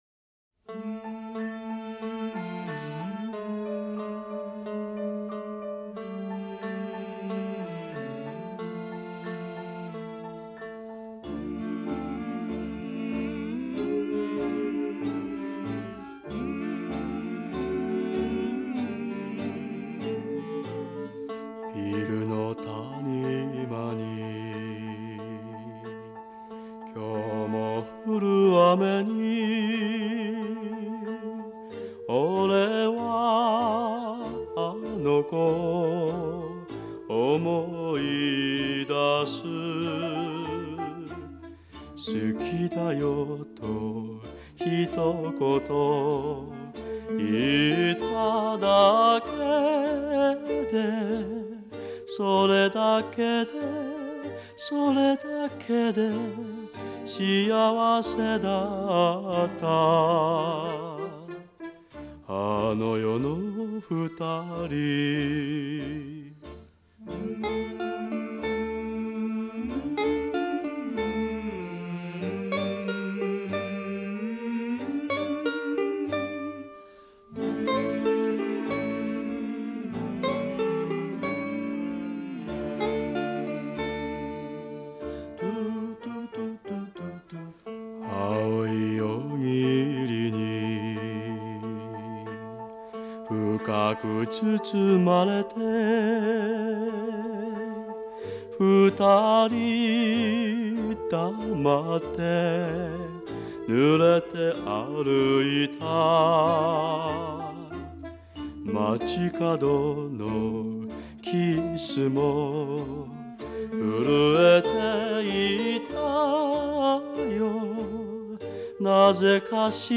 Жанр: enka